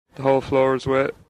‘The Whole Floor is Wet’ with glottalisation of final /-t/ [-ʔ] (local Dublin speaker)